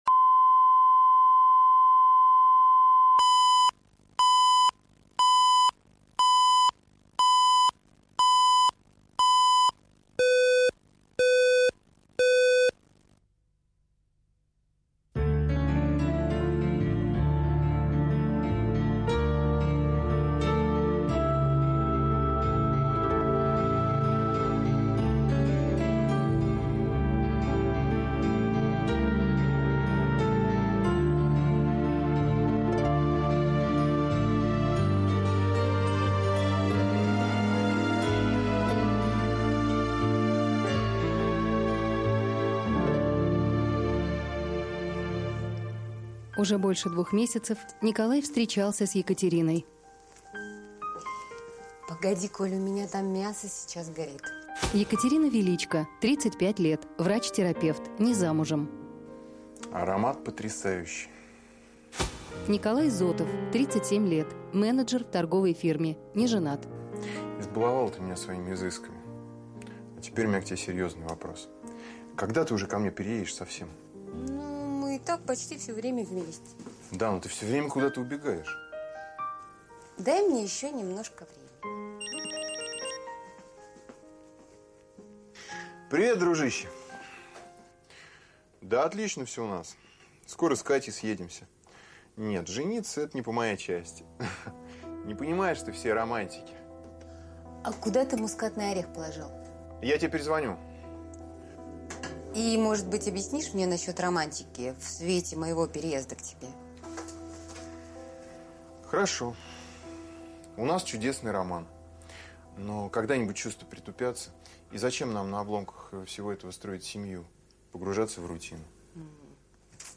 Аудиокнига Про любовь от А до Я | Библиотека аудиокниг